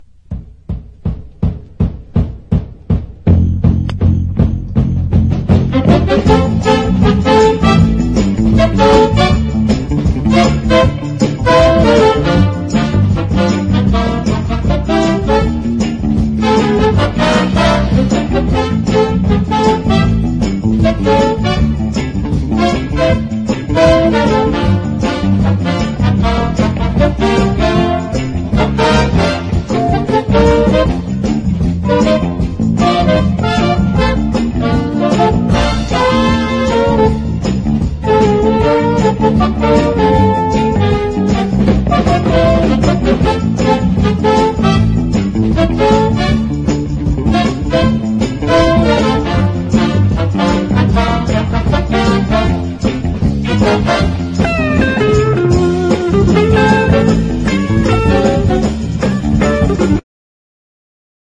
EASY LISTENING / OST / NEW WAVE / 80'S
カラフルなニューウェイヴ/モダンポップ名曲が並んだ80'Sサーフ・サントラ！